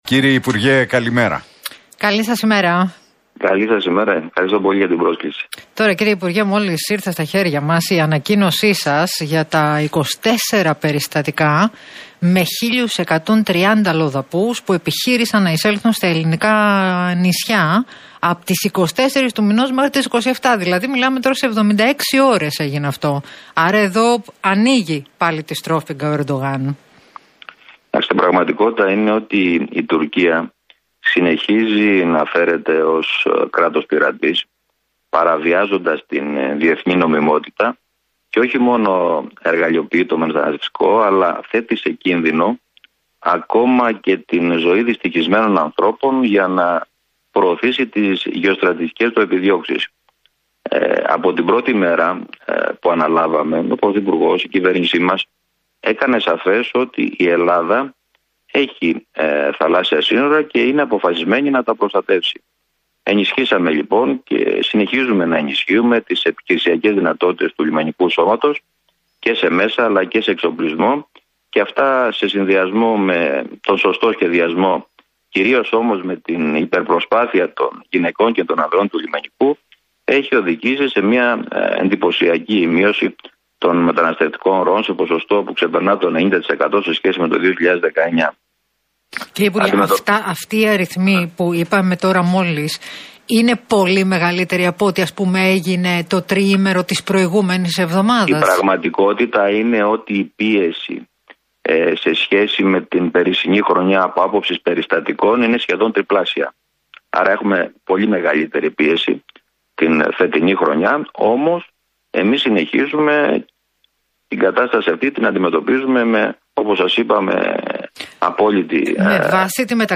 Πλακιωτάκης στον Realfm 97,8: Η Τουρκία συνεχίζει να φέρεται ως κράτος - πειρατής